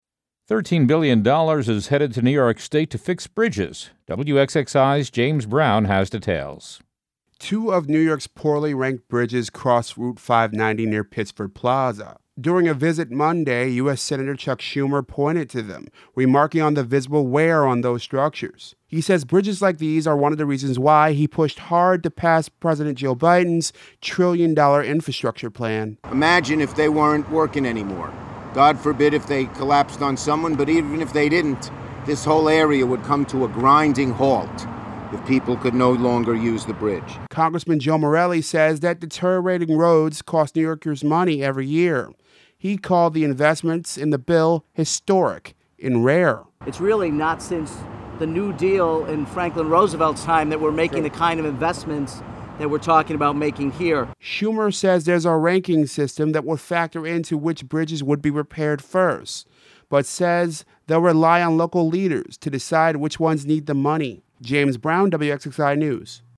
Rep. Joe Morelle, who was at the press conference, called the conditions of New York's bridges and highways unacceptable.